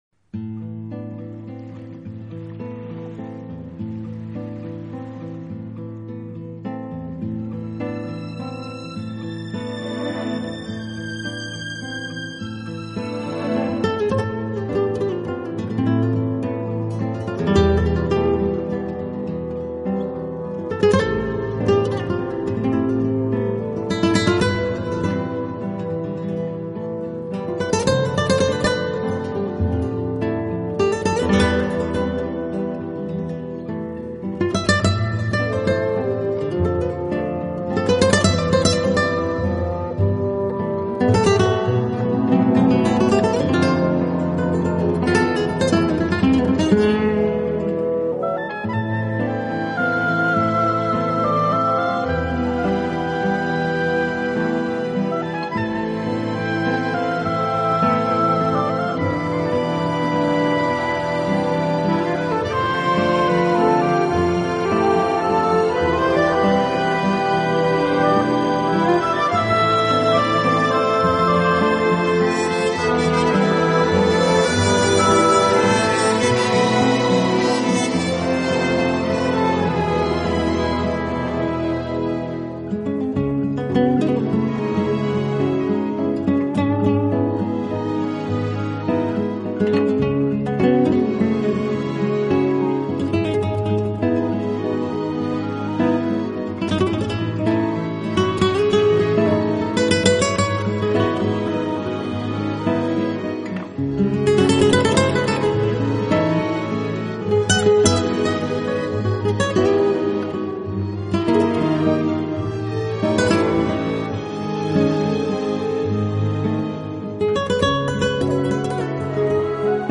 类 型：Flamenco / Latin